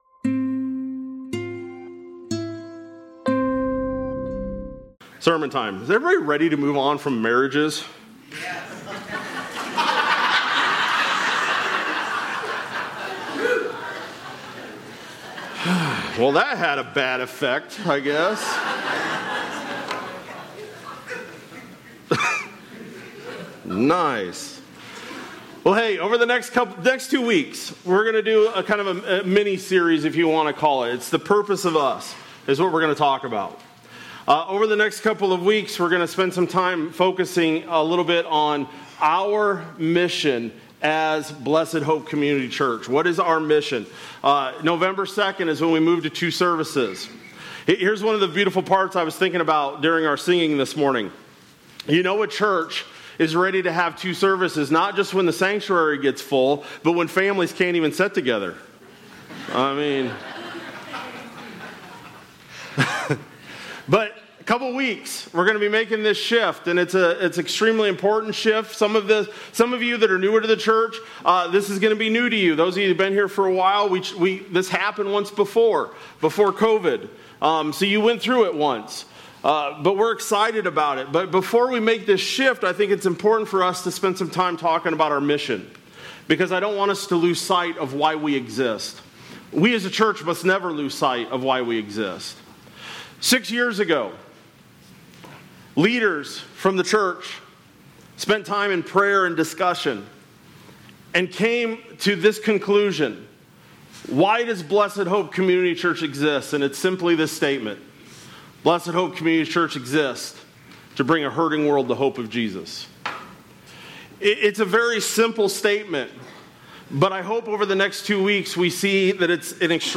Oct-19-25-Sermon-Audio.mp3